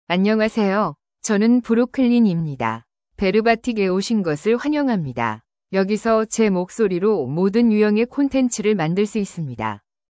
FemaleKorean (Korea)
BrooklynFemale Korean AI voice
Brooklyn is a female AI voice for Korean (Korea).
Voice sample
Listen to Brooklyn's female Korean voice.